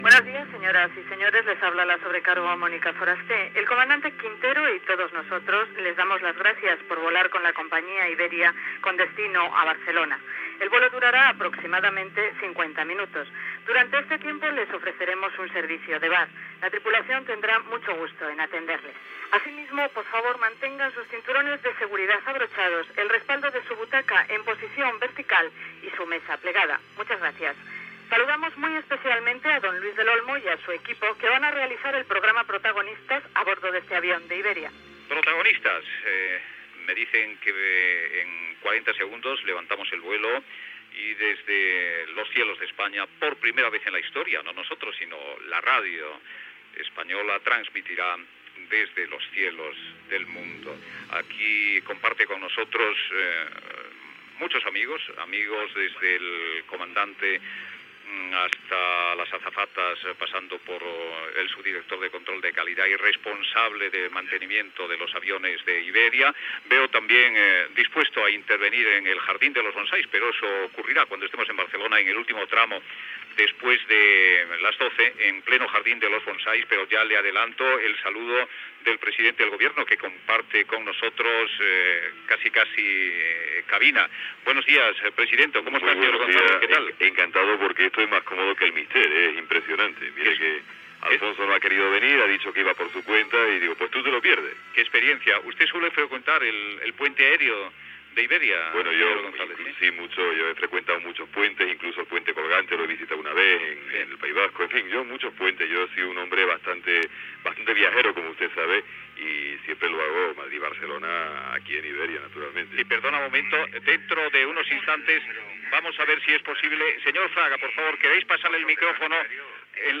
Programa emès des d'un Boeing 727 d'Iberia. Des del terminal del pont aeri Bardelona Madrid a l'aeroport de Barajas.
Info-entreteniment